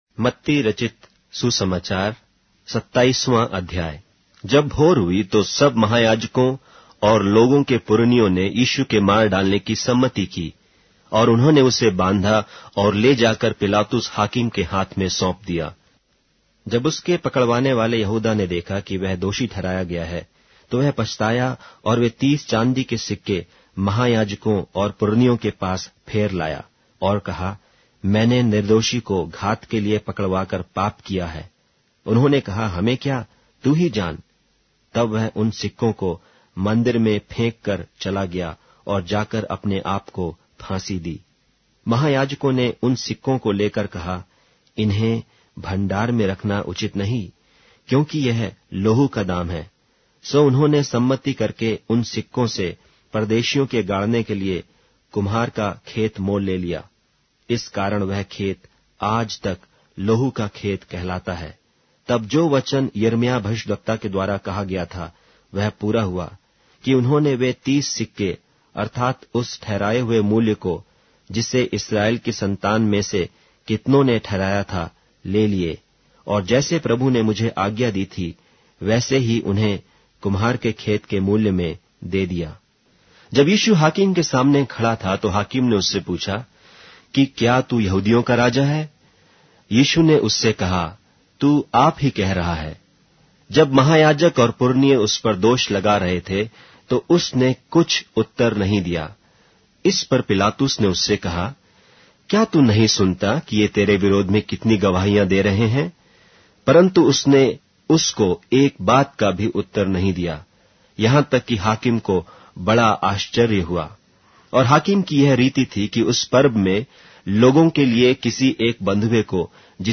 Hindi Audio Bible - Matthew 5 in Hov bible version